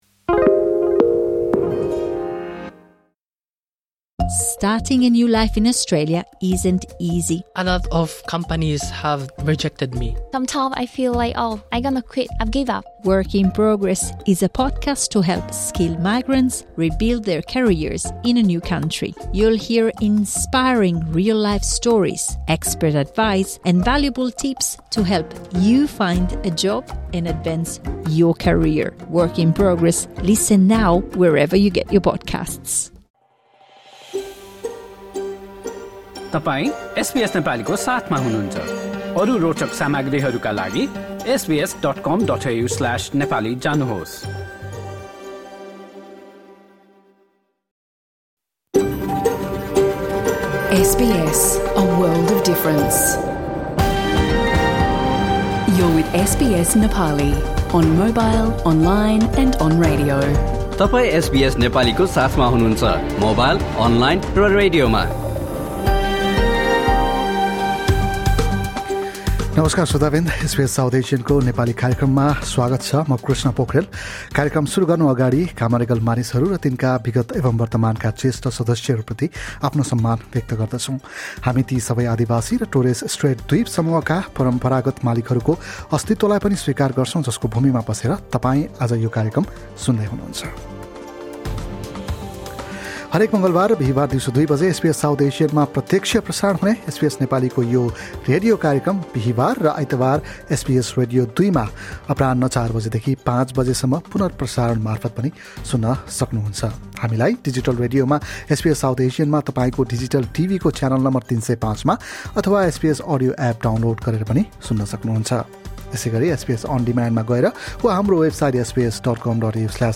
मङ्गलवार, ११ नोभेम्बर सन् २०२५ दिउँसो २ बजे एसबीएस साउथ एसियनमा प्रत्यक्ष प्रसारण भएको एसबीएस नेपालीको रेडियो कार्यक्रम सुन्नुहोस्। यस कार्यक्रममा हामीले पछिल्लो एक हप्ताका अस्ट्रेलिया सम्बन्धी समाचार लगायत मासिक आर्थिक कुराकानी, रिमेम्ब्रेन्स डे र रग्बी खेलका बारेमा कुरा गरेका छौँ।